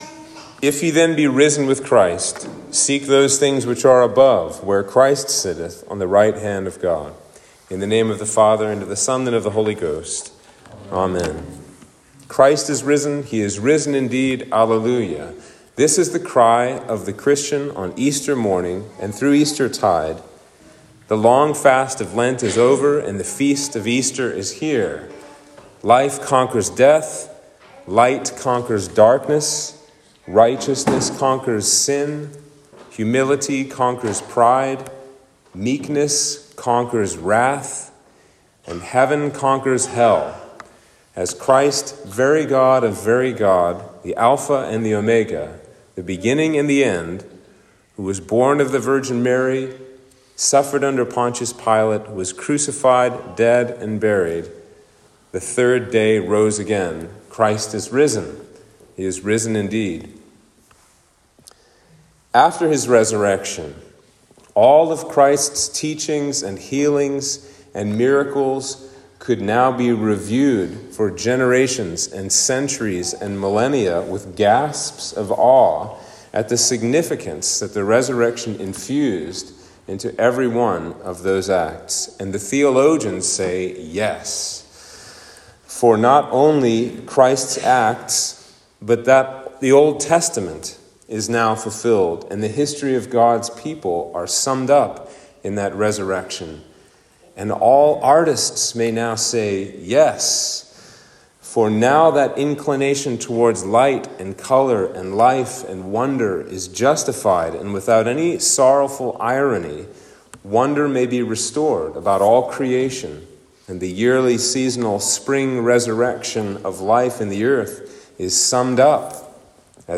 Sermon for Easter Day